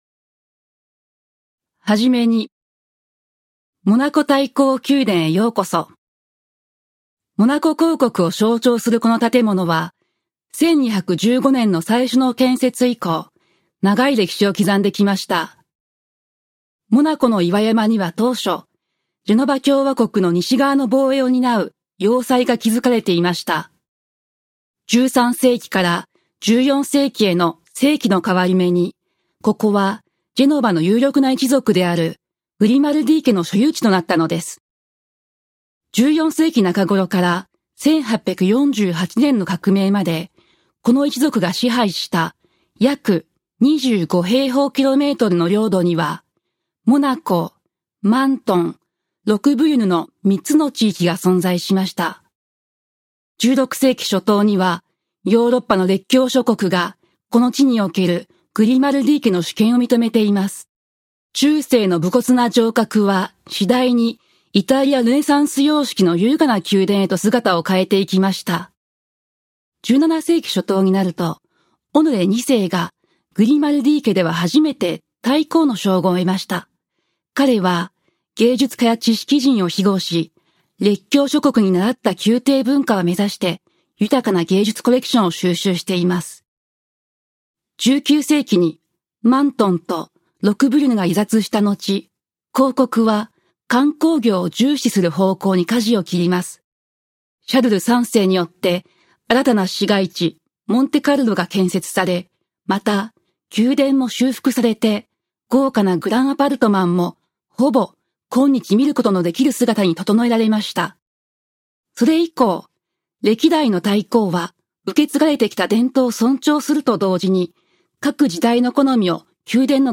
Audioguide en japonais
Comédienne japonaise née au Japon arrivée en France il y a 20 ans.